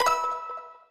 Appear_Scatter_Sound.mp3